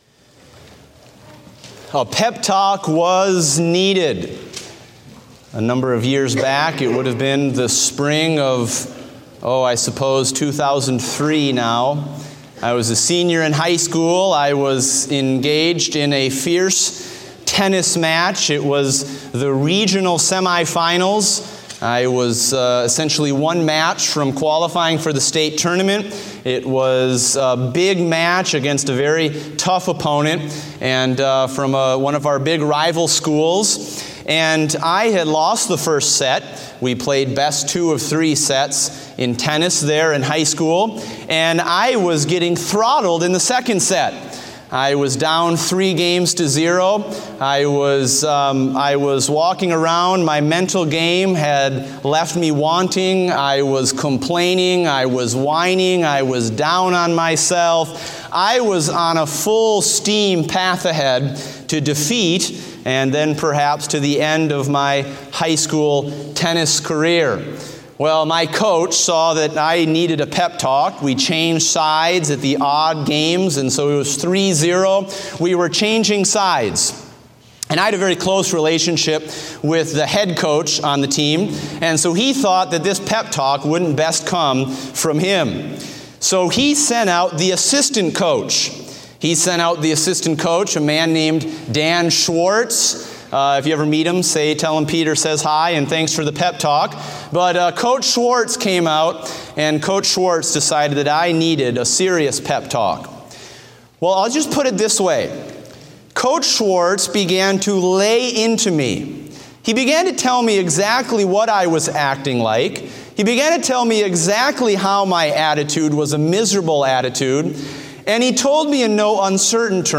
Date: May 8, 2016 (Morning Service)